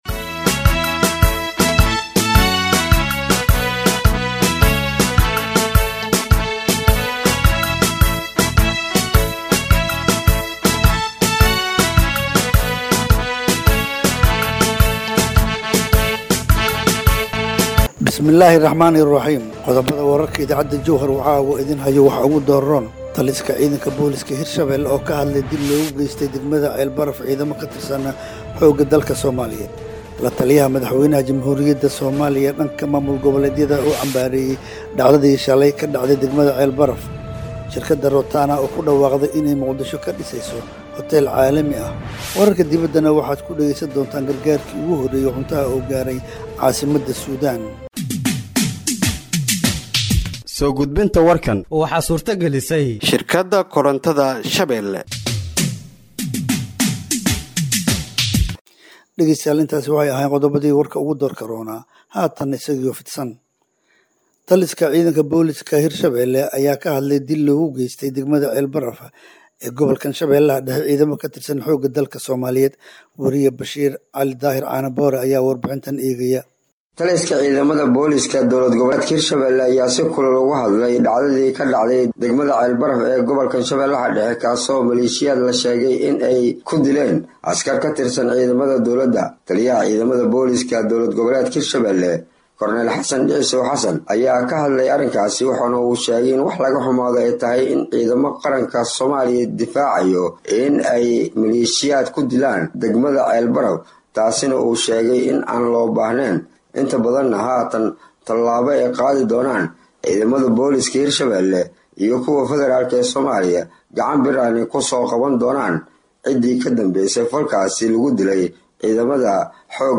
Halkaan Hoose ka Dhageeyso Warka Habeenimo ee Radiojowhar